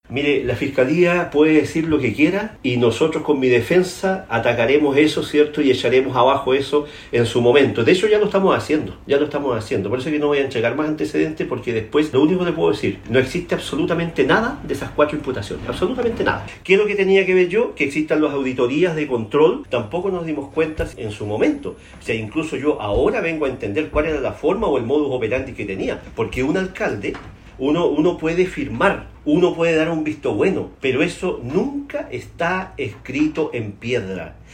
En el comedor de su casa, donde cumple la cautelar de arresto domiciliario total, con lápiz, papel y un vaso de agua, el exalcalde de Puerto Montt, Gervoy Paredes, conversó con Radio Bío Bío y defendió su inocencia afirmando que junto a su defensa tienen antecedentes que desestiman los cuatro delitos de corrupción que se le imputan al momento de liderar la capital regional de Los Lagos.